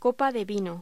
Locución: Copa de vino
voz